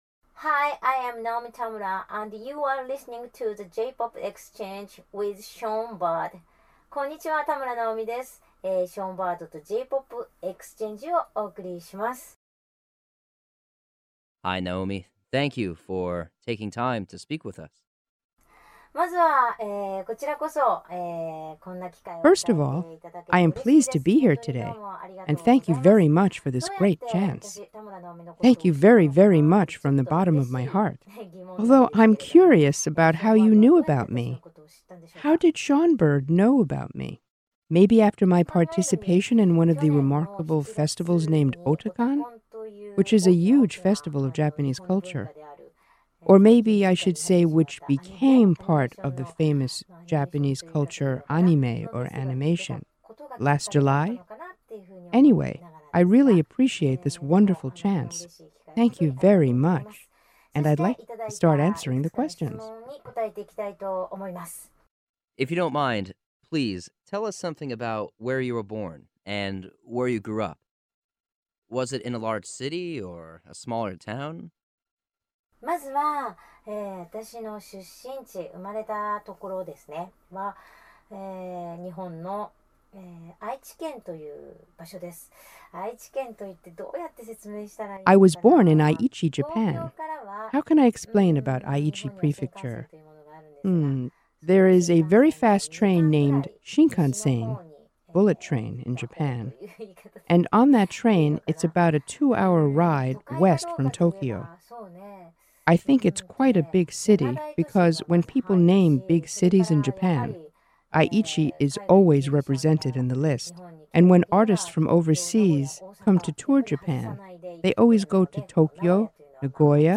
The J-Pop Exchange: Naomi Tamura Exclusive Interview
View Transcript of Radio Interview